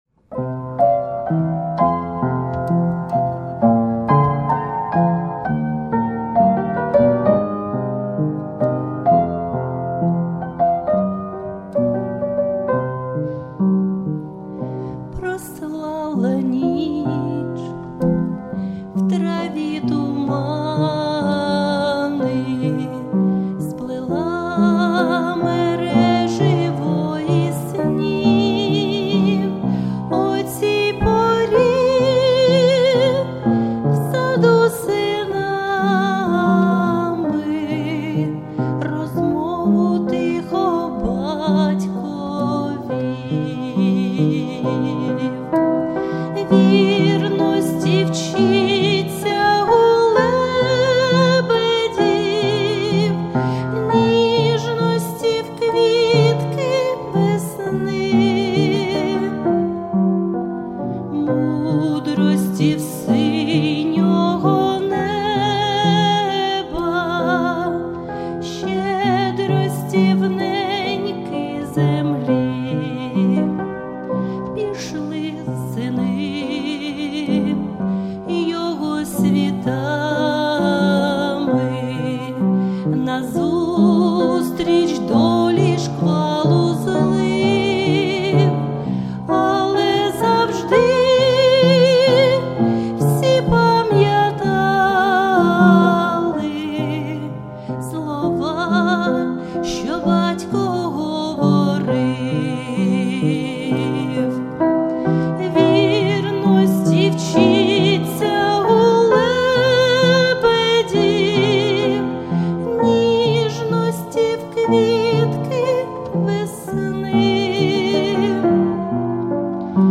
музика та виконання
такий гарний приспів 12 як же я обожнюю фортепіано 16 16 16